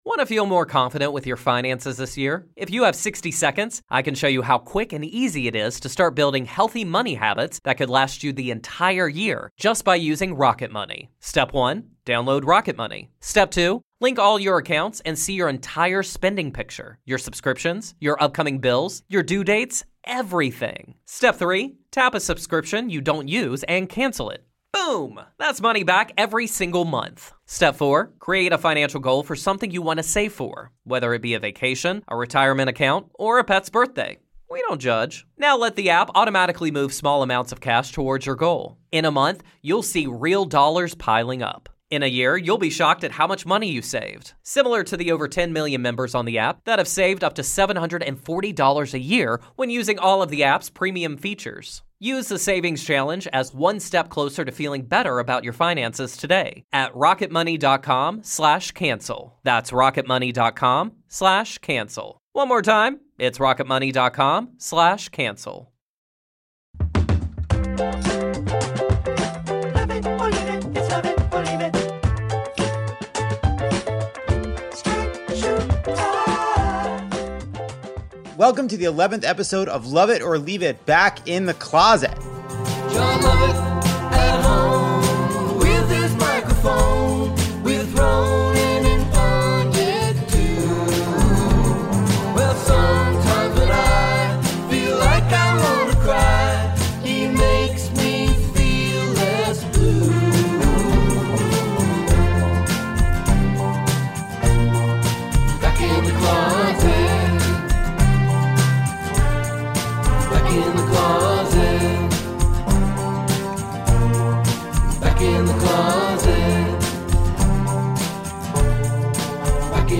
Senator Elizabeth Warren is here to talk about oversight, the pandemic response, and that question she won’t answer.
Kal Penn is back and judges the monologue.
We also bring back the rant wheel and we hear your high notes.